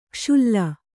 ♪ kṣulla